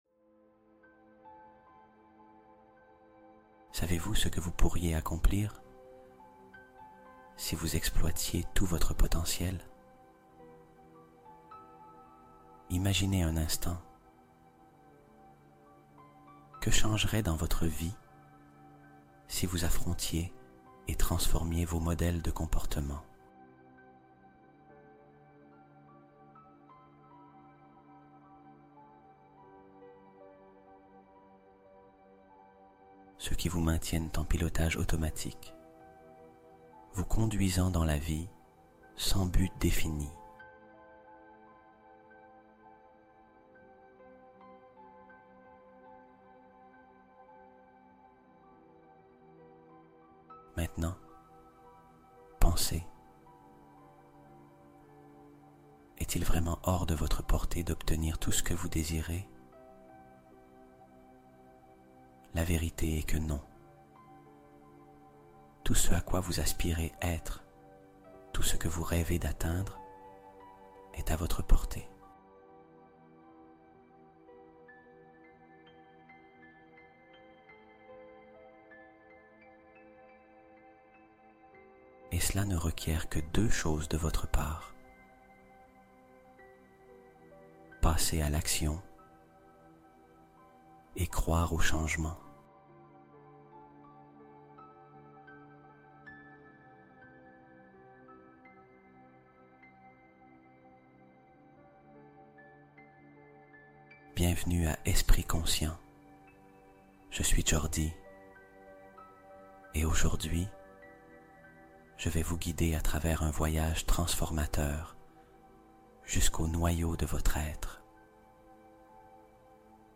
Futur Harmonieux : Méditation du soir pour préparer ses succès de demain